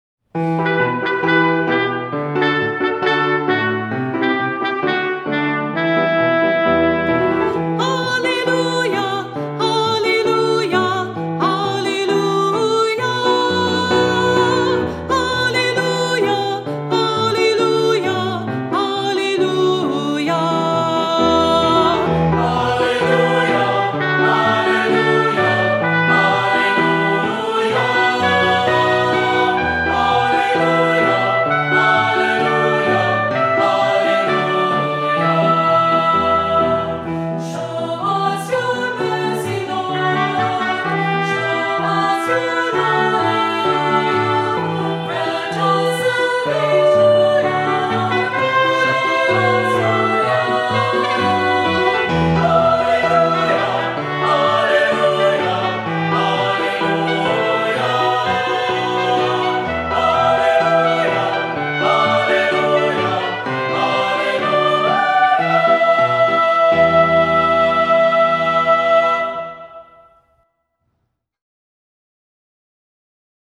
Voicing: Unison Children’s Choir, Descant, Cantor